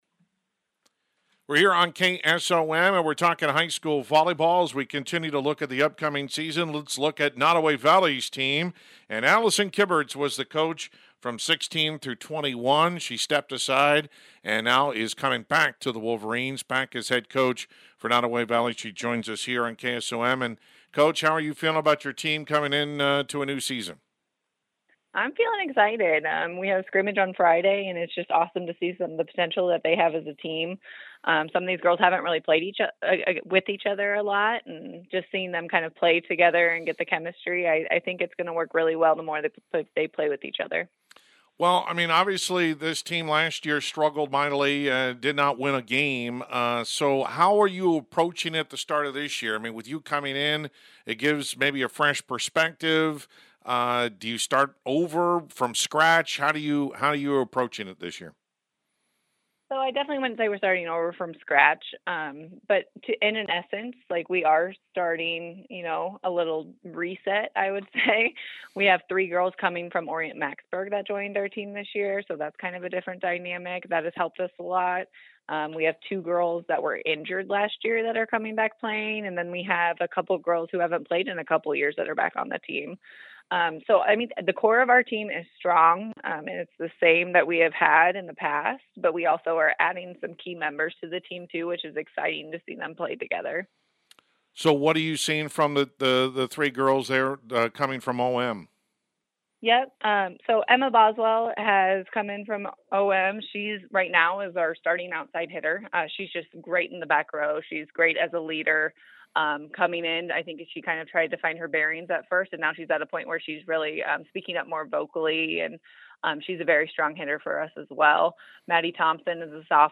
Complete Interview
nodaway-valley-volleyball-8-28.mp3